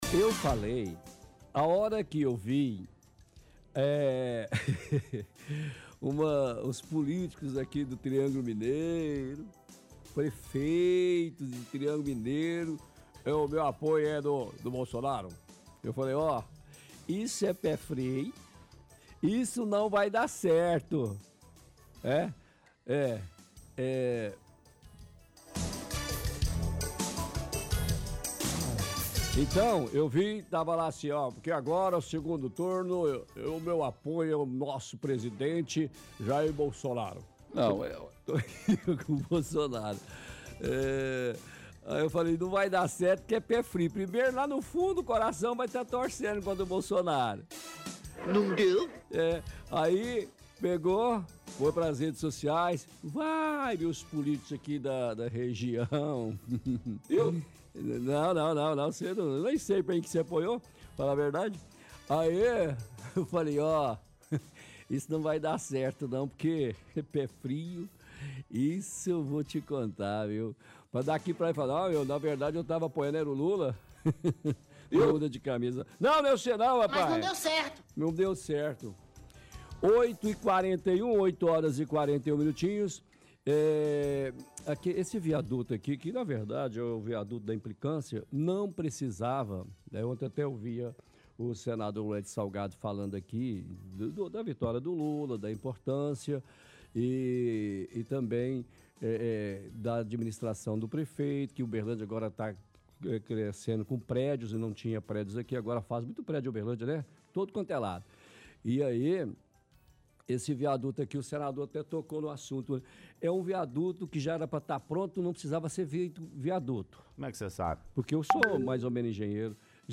Utiliza áudios do prefeito para fazer piadas.